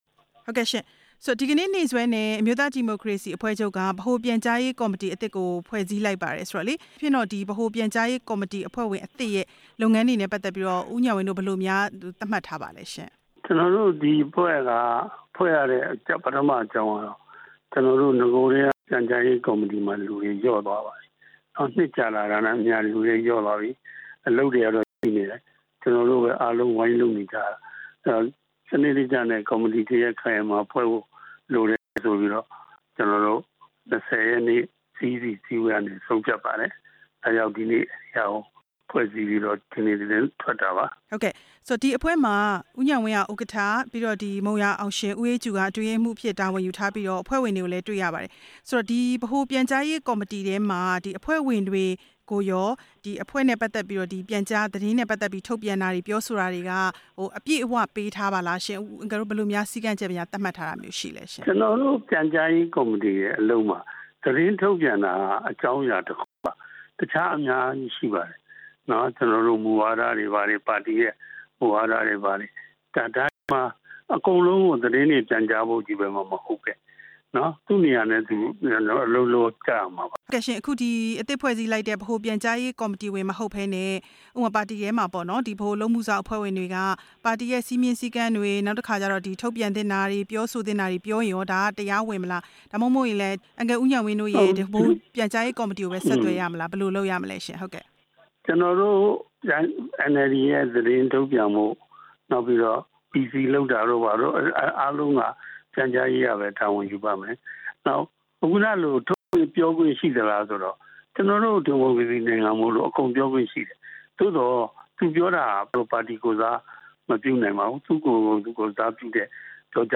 ဆက်သွယ်မေးမြန်းထားပါတယ်